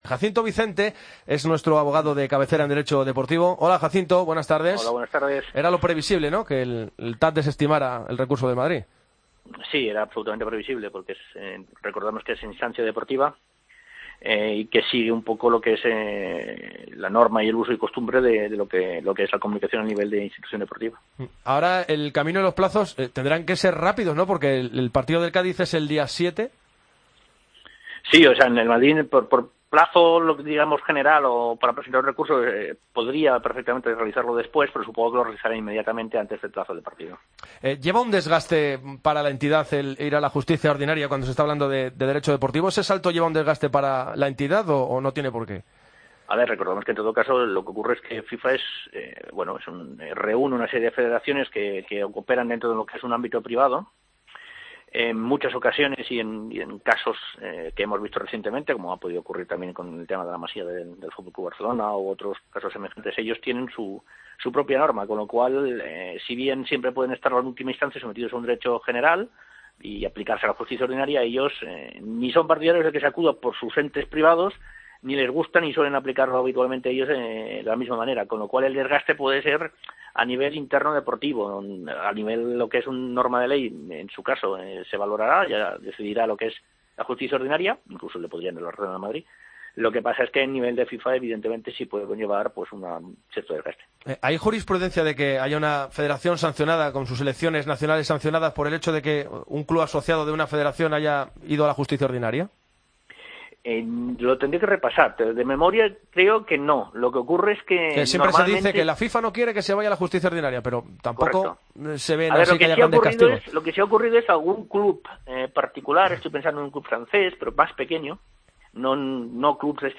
experto jurídico, explica la resolución del TAD en Deportes COPE